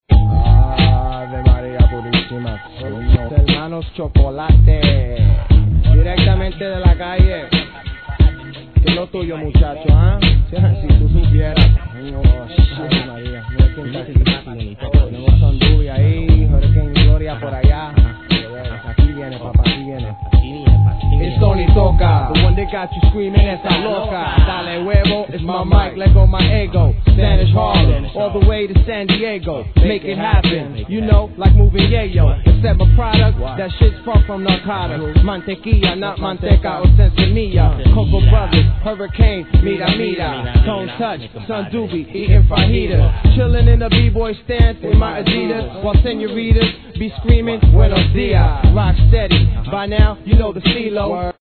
HIP HOP/R&B
どれも地下臭プンプンです!